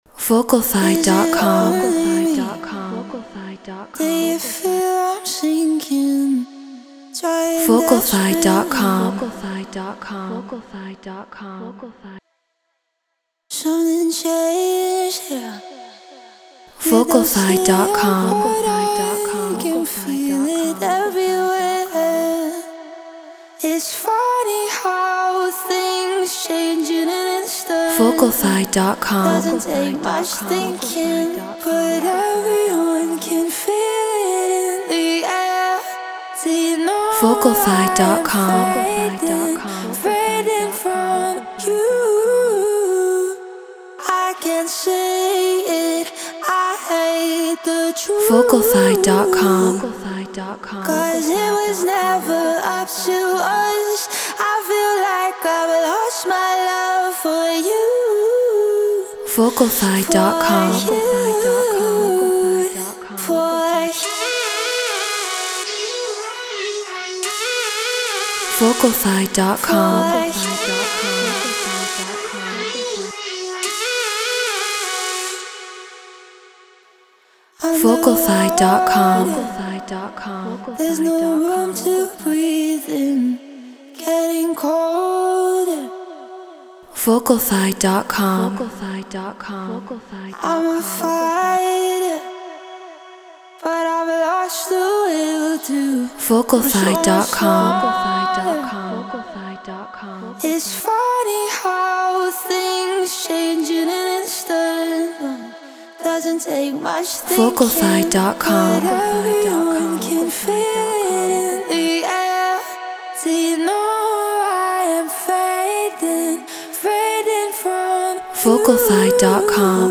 Get Royalty Free Vocals.